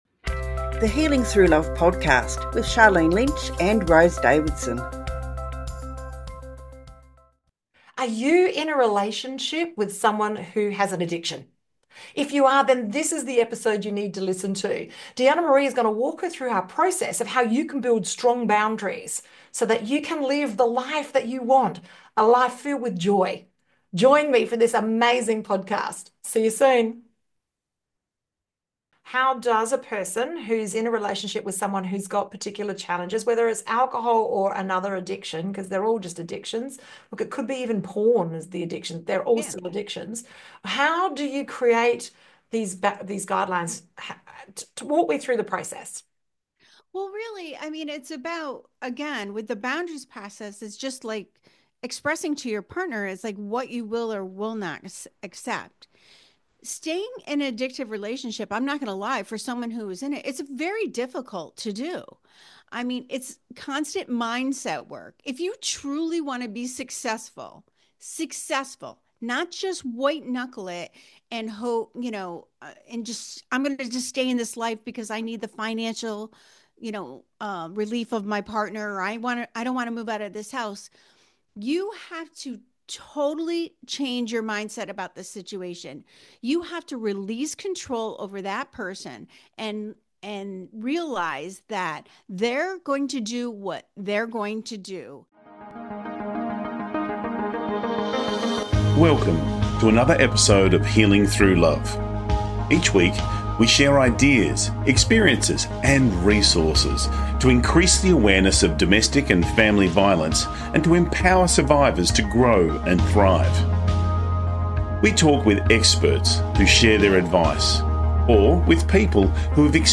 Key Points from the Interview: You can love again after a 20-year toxic marriage—healing takes time, but it’s possible.